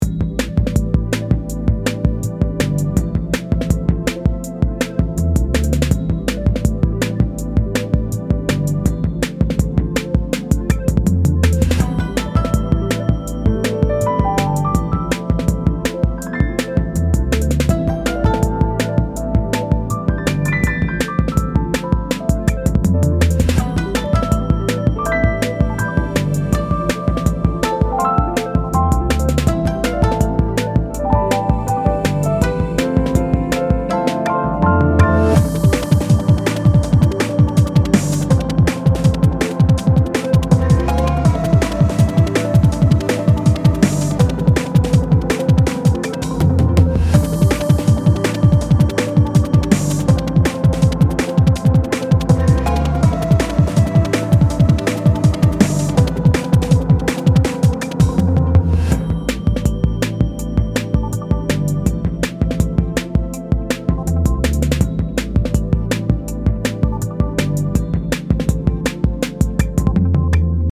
【リーチ時のBGM】